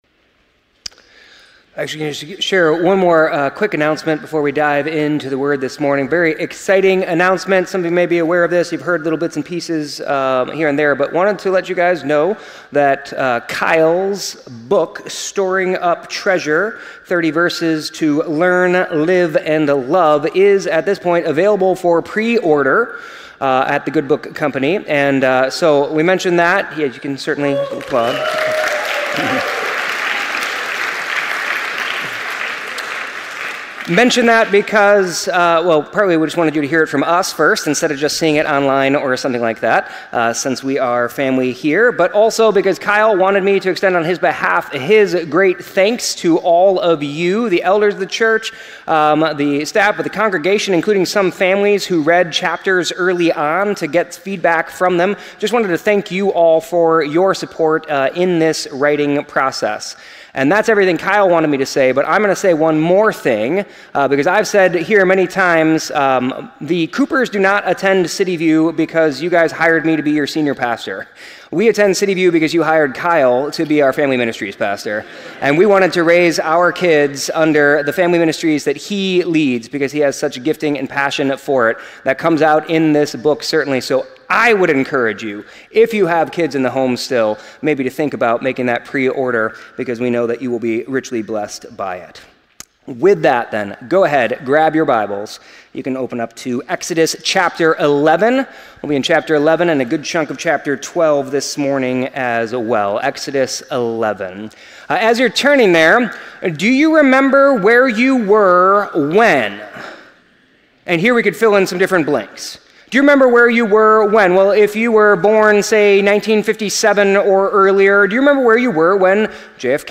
The sermon explores how to overcome insecurity not by trying harder, but by trusting in God’s power and wisdom. It examines the story of Daniel interpreting Nebuchadnezzar’s dream, highlighting Daniel’s humble wisdom and reliance on God. The sermon emphasizes the transience of earthly kingdoms and the ultimate triumph of God’s eternal kingdom.